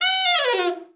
ALT FALL   2.wav